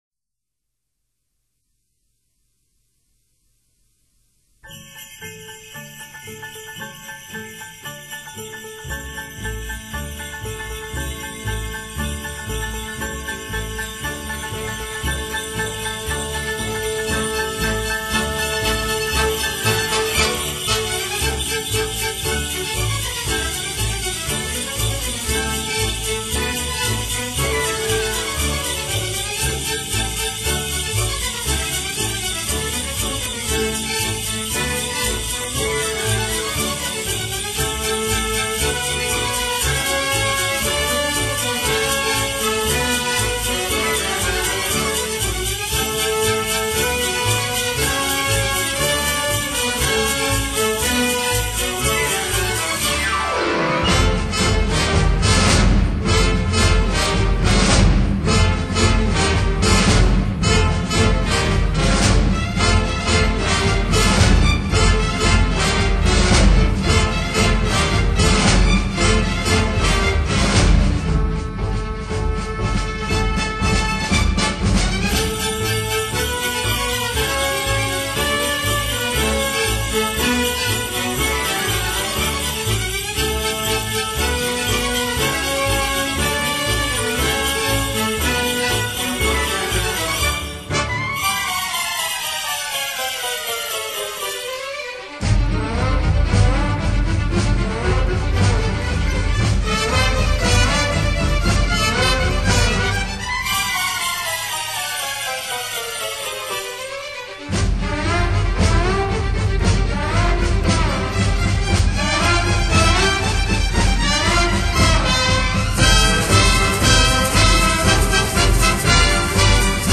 它所表现的平衡感非常棒，
此碟收录许多西班牙民族风格曲目，无论您收集过哪一张西班牙音乐碟，都可以在里面找到它的影子。